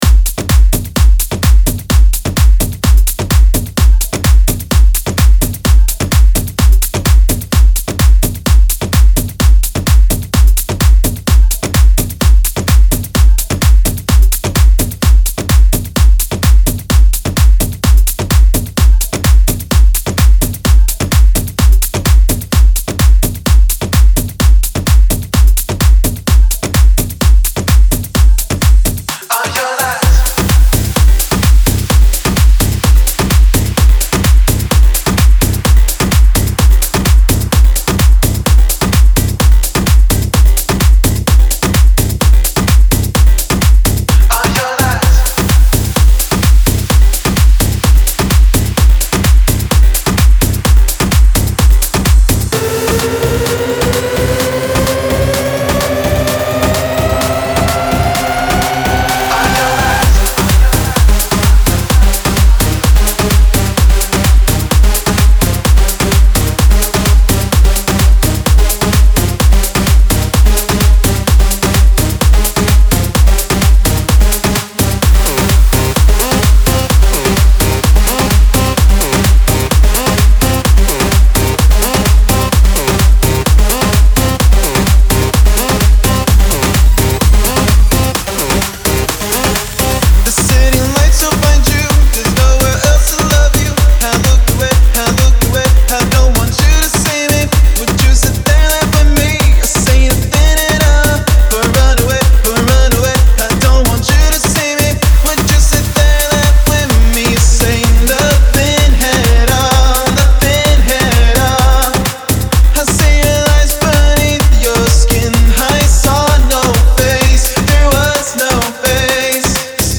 Big Room Remix